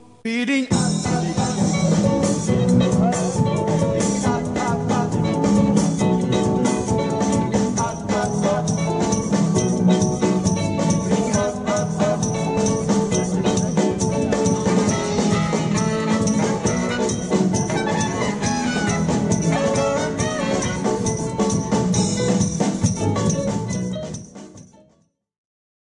musique ZOUK